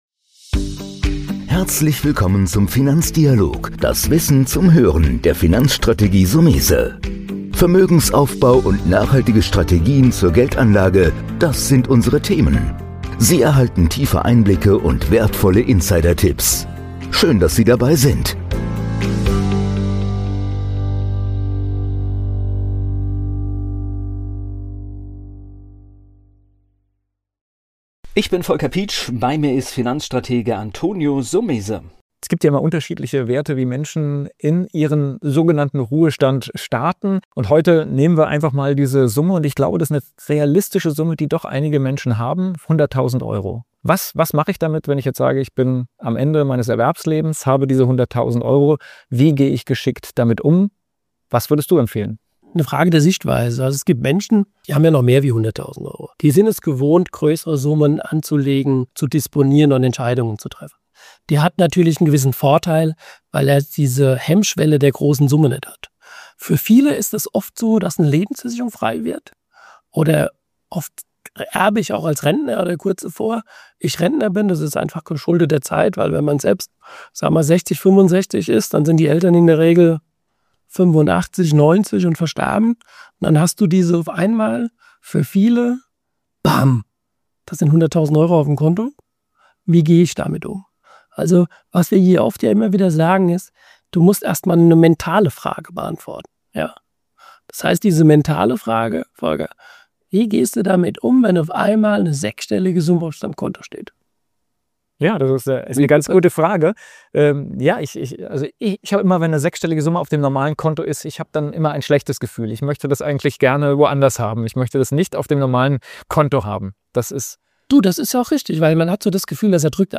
DIALOG MODERIERT